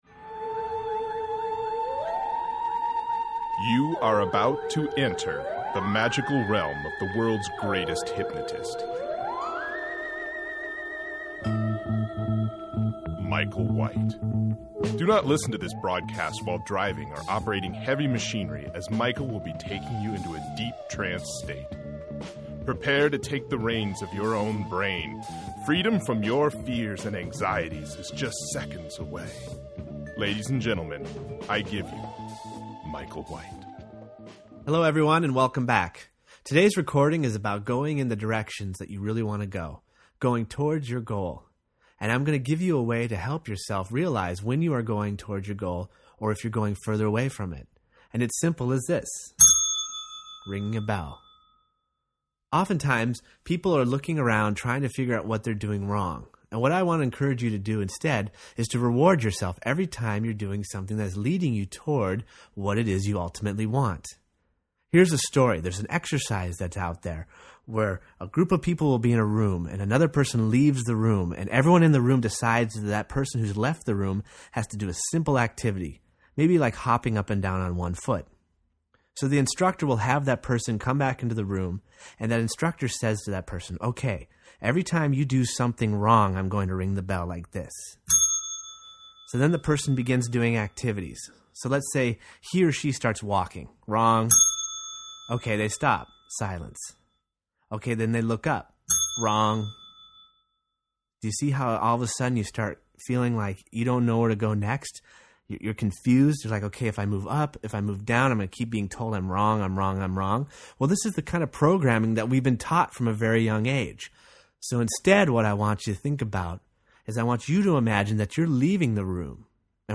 Hypnosis